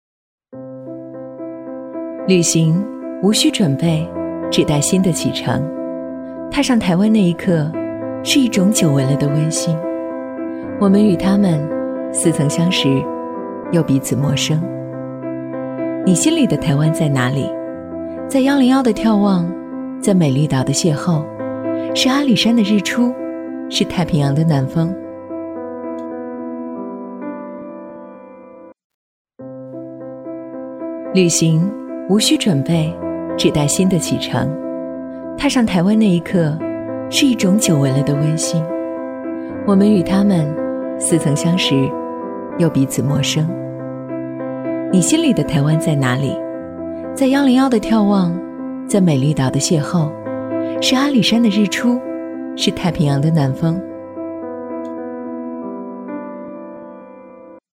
• 女S12 国语 女声 独白-旅行台湾-个人独白风格 神秘性感|调性走心|亲切甜美|感人煽情|素人